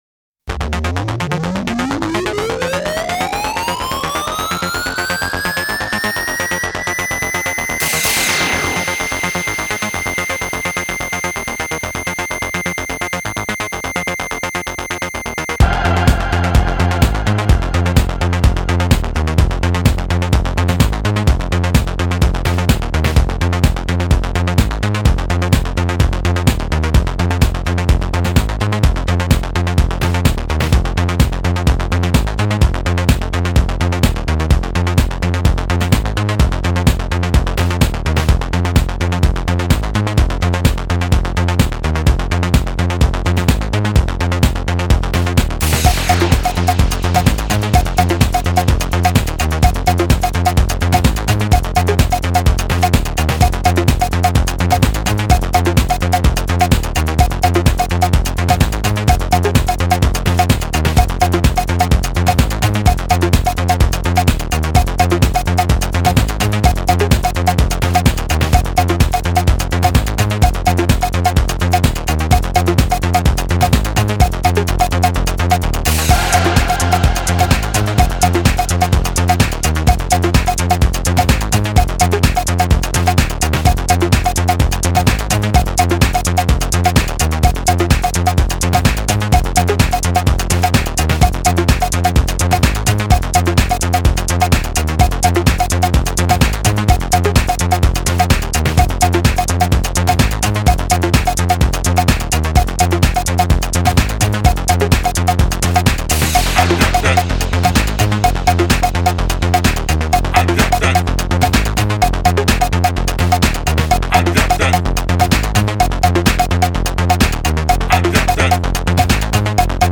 I think it needs more cowbell though 🙂